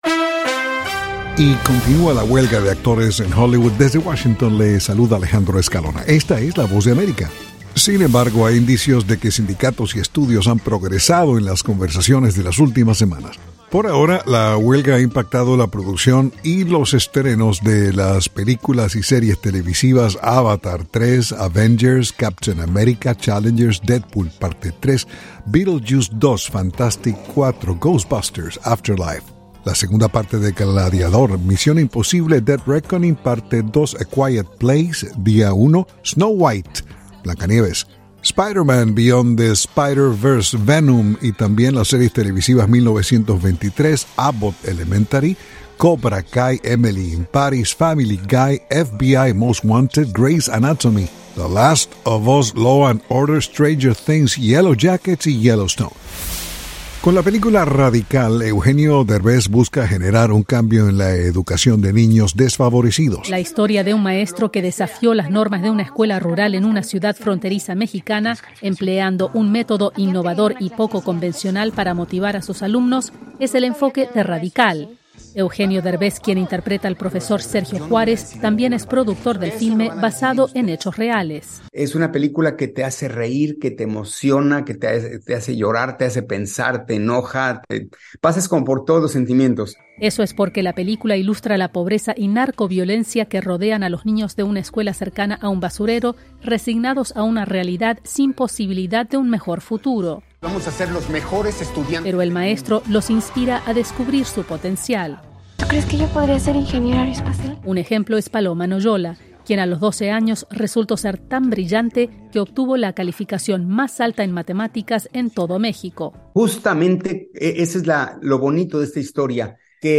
noticias del espectáculo